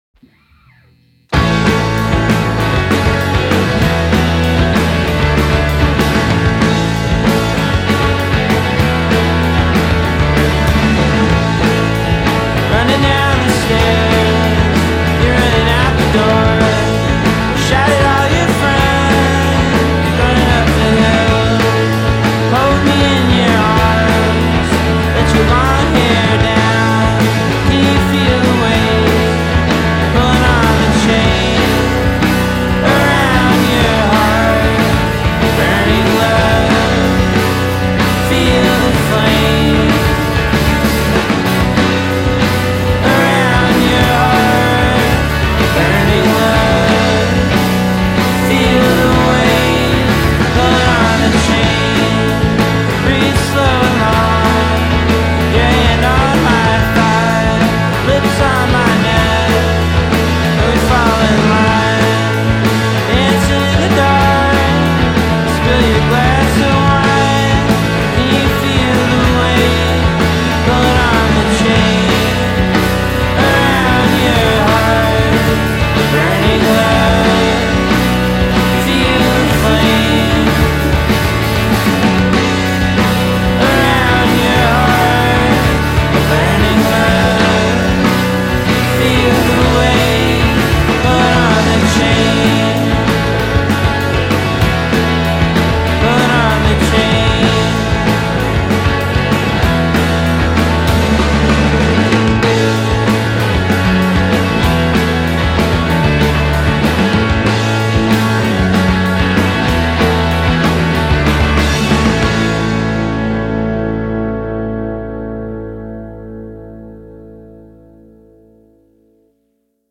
frenetici